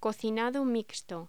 Locución: Cocinado mixto
voz